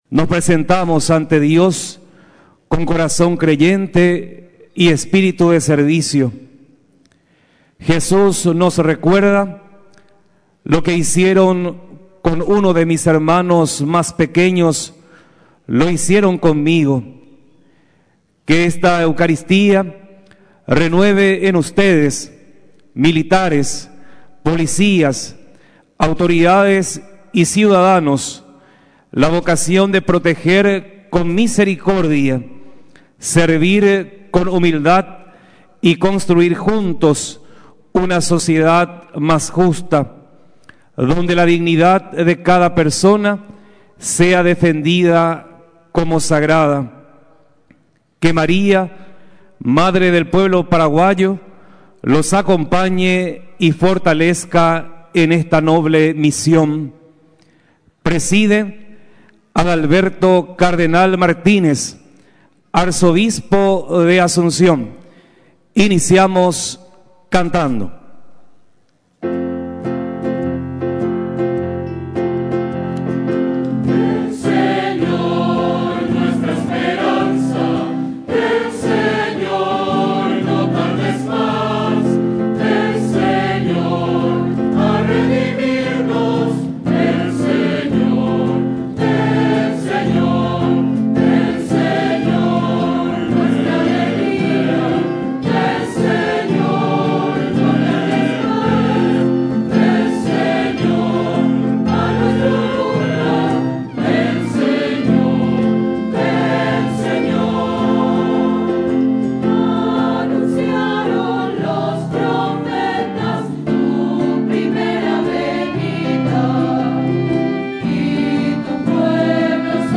Los enfermos, indígenas, adictos a estupefacientes, adultos mayores abandonados, carenciados, ya no tienen que ser descartados por las autoridades ni la ciudadanía, sino que deben recibir la asistencia necesaria para salir de sus miserias, exhortó este jueves durante el séptimo día del novenario a la Virgen de Caacupé, el cardenal Adalberto Martínez.